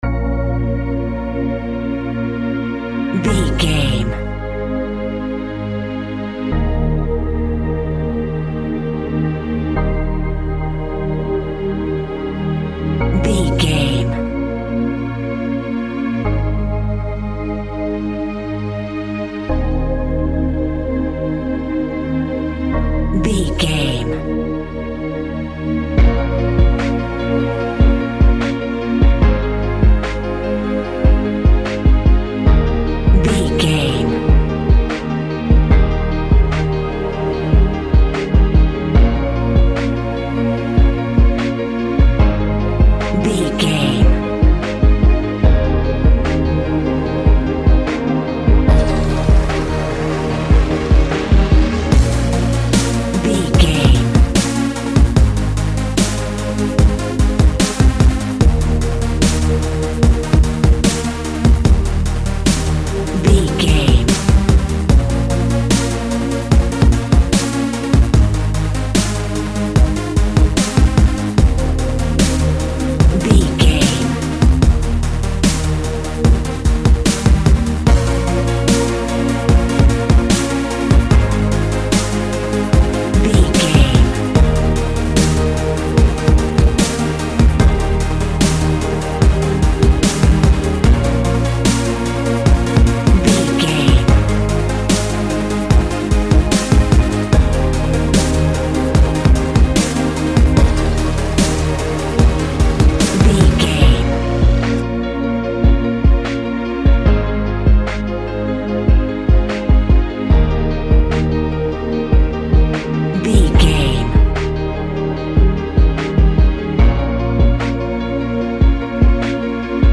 Synth Pulse Dubstep Hip Hop.
Aeolian/Minor
smooth
strings
drums
drum machine
synthesiser
dubstep
breakbeat
energetic
synth leads
synth bass